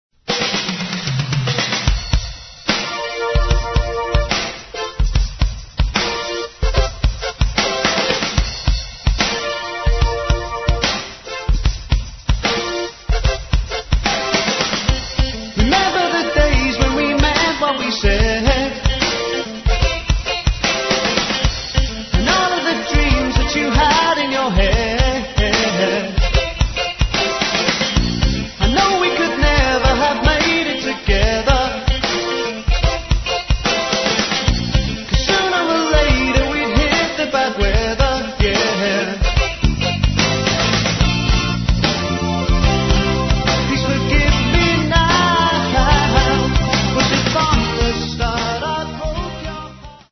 Diploma Studio, Malden, Essex 1990.